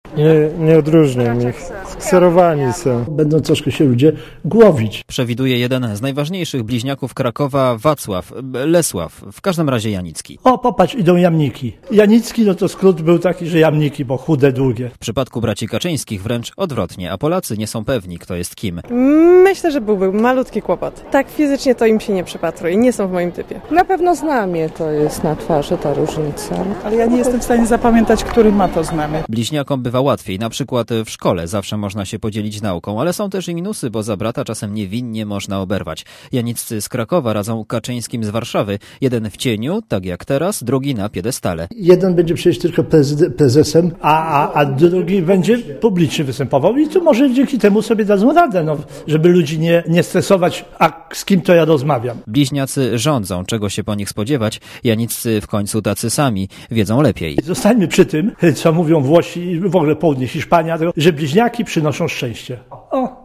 Źródło zdjęć: © PAP 02.11.2005 | aktual.: 03.11.2005 08:06 ZAPISZ UDOSTĘPNIJ SKOMENTUJ Relacja reportera Radia ZET